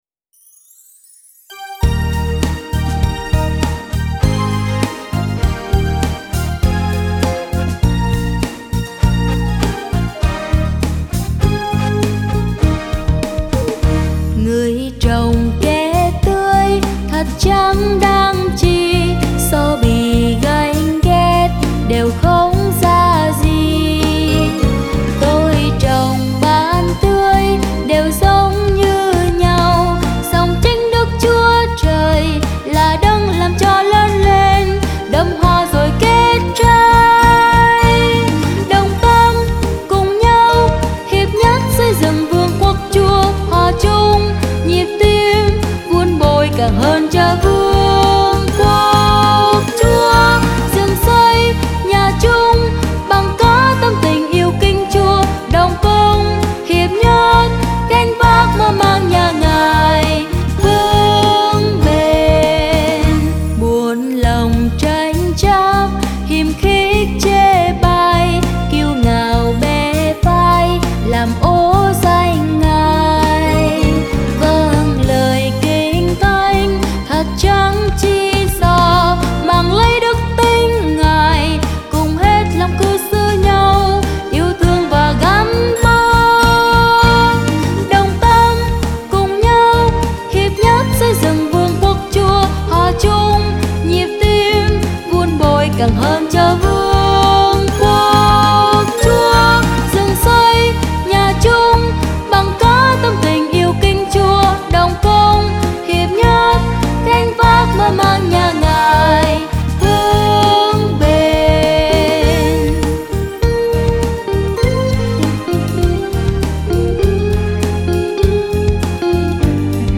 Nhạc Thánh Sáng Tác Mới